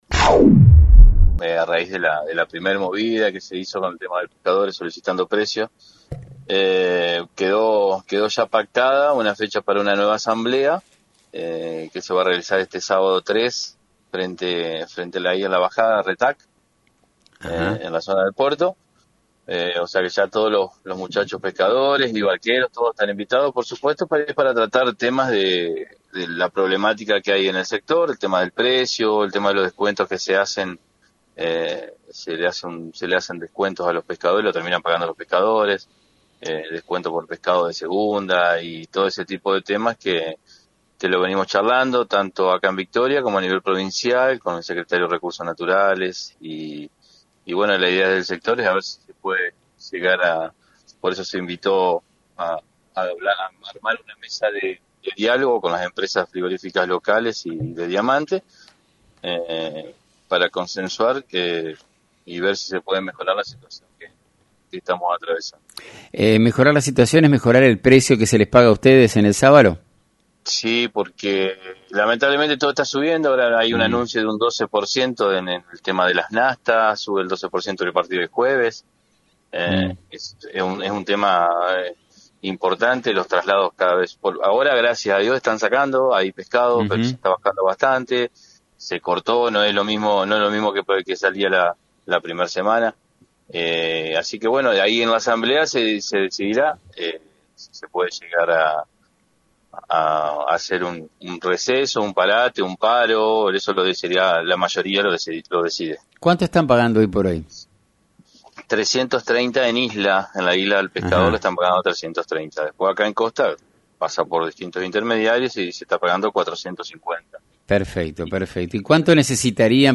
En una entrevista previa al evento, se planteó la posibilidad de un receso, parate o incluso un paro, siendo estas decisiones que quedarán en manos de la mayoría de los pescadores presentes en la asamblea.